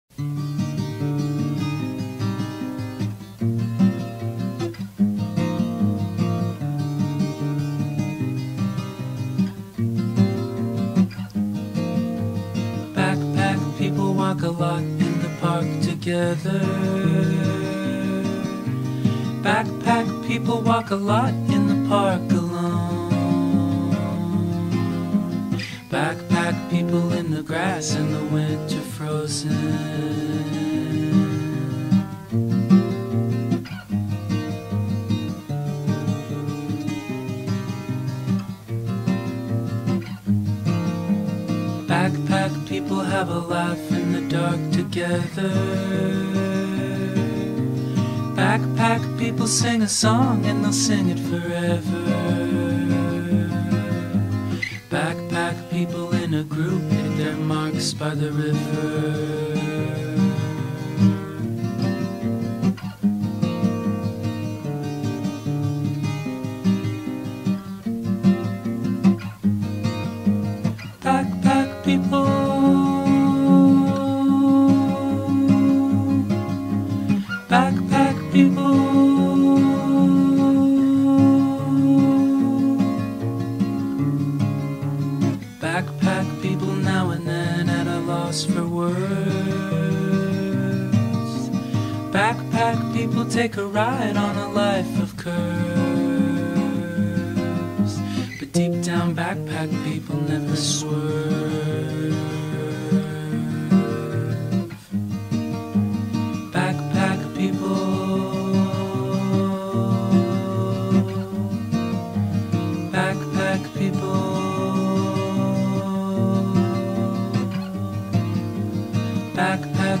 موسیقی آرامش بخش Alternative/Indie